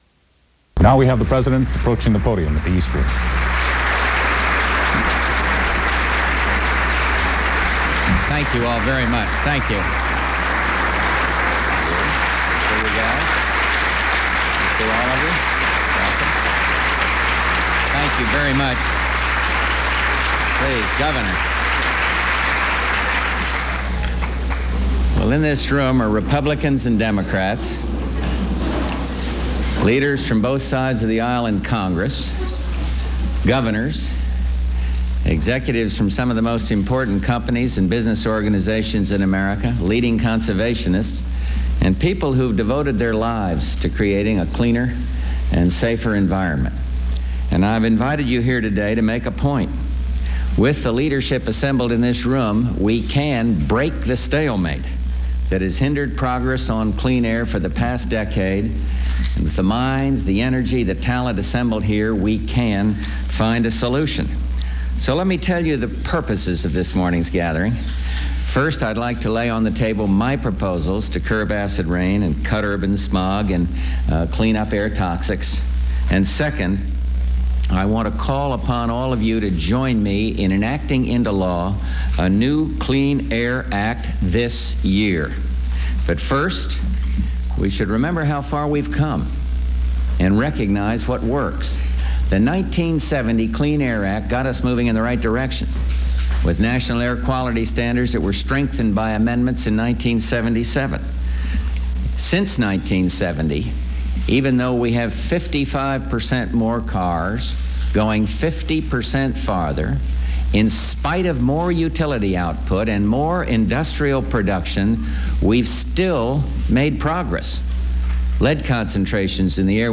U.S. President George Bush speaks on environmental issues